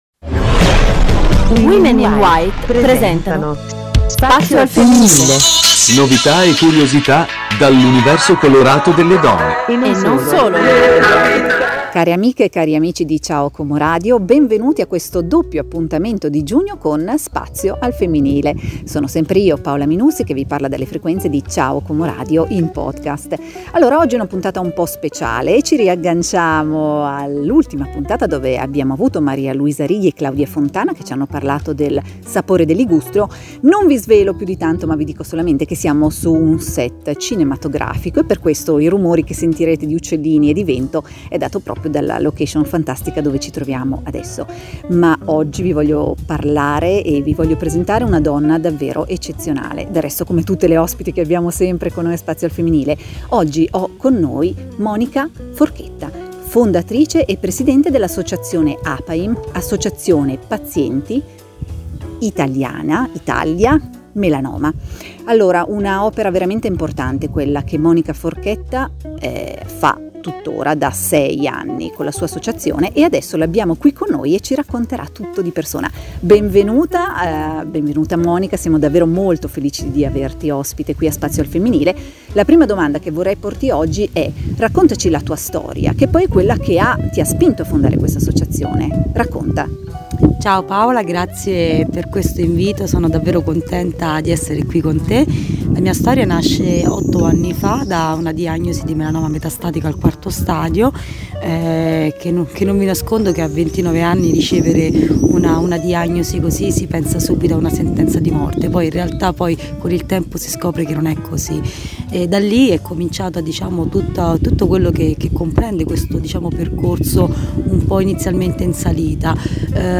Amiche per la pelle. Intervista